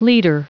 Prononciation du mot litre en anglais (fichier audio)
Prononciation du mot : litre